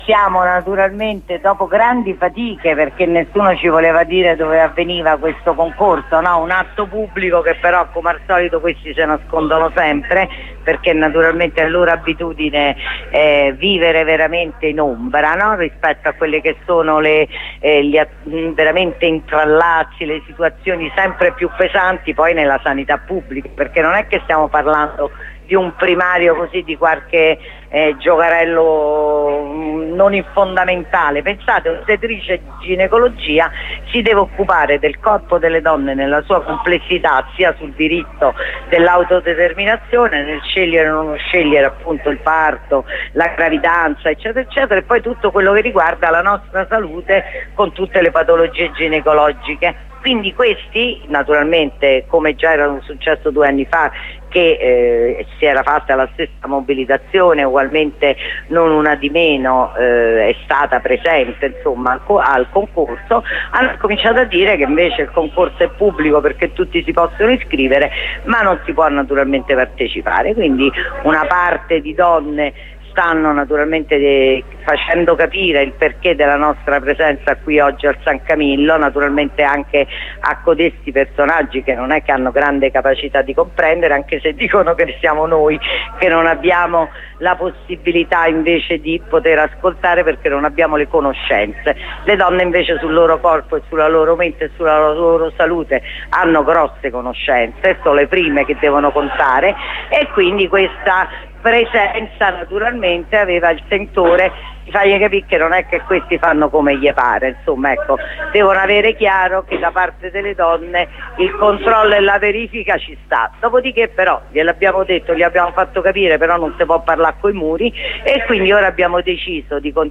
La corrispondenza con una redattrice della radio.